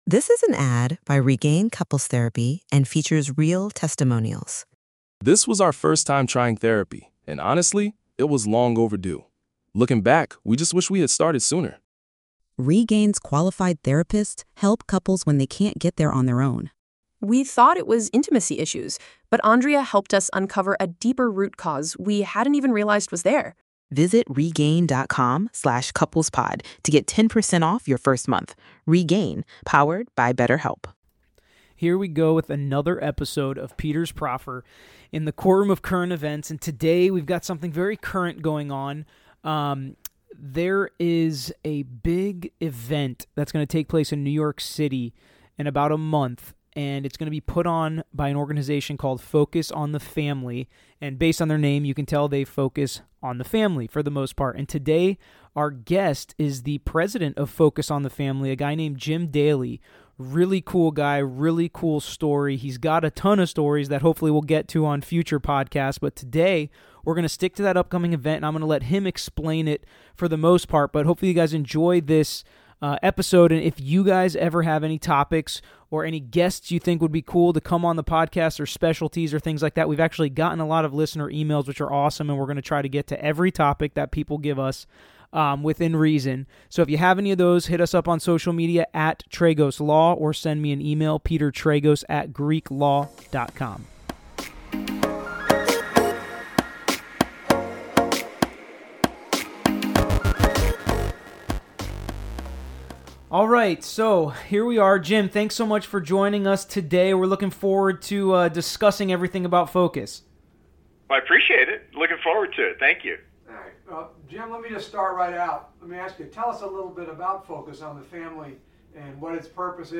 Focus on the Family - An Interview